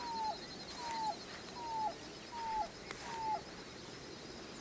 Papa-lagarta-acanelado (Coccyzus melacoryphus)
Nome em Inglês: Dark-billed Cuckoo
Fase da vida: Adulto
País: Argentina
Província / Departamento: Entre Ríos
Condição: Selvagem
Certeza: Gravado Vocal
cuclillo-canela.mp3